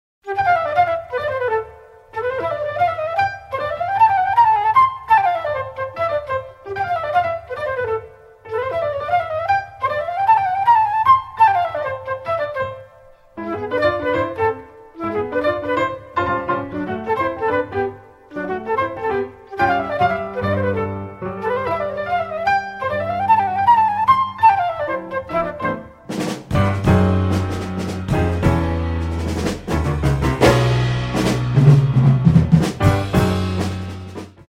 The Best In British Jazz
Recorded at Wave Studios, 25th / 26th September 1983